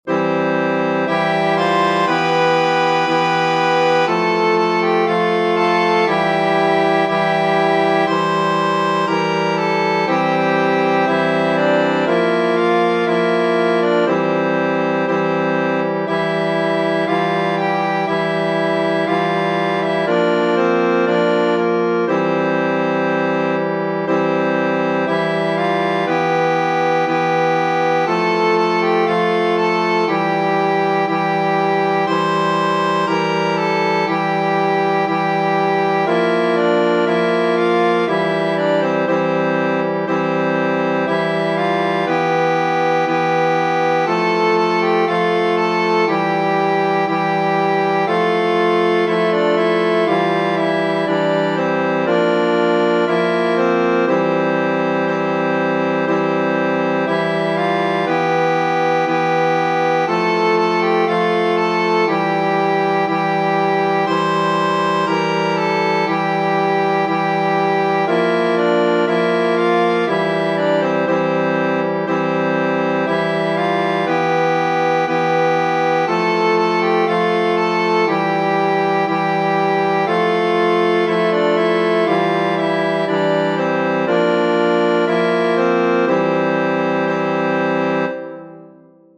Tradizionale Genere: Religiose Testo di anonimo Sia laudato San Francesco, quelli c’aparve en croce fixo, como redemptore.